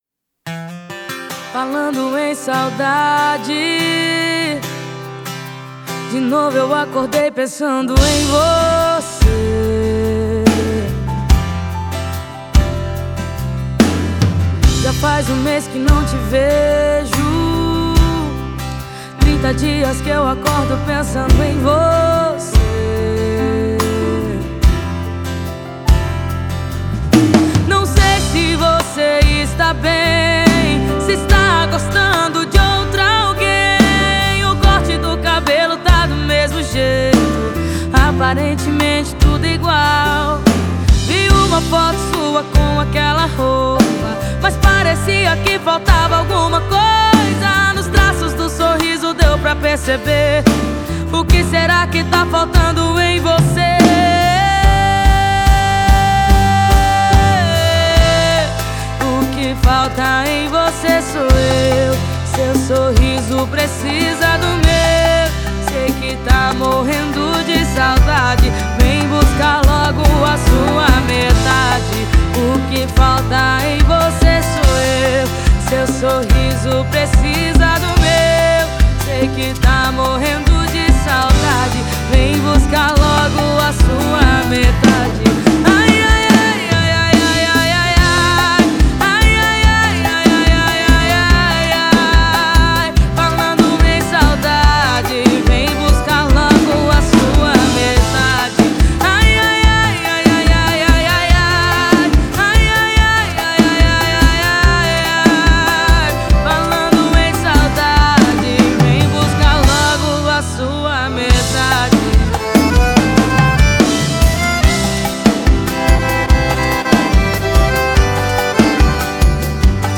2025-01-02 18:44:39 Gênero: Sertanejo Views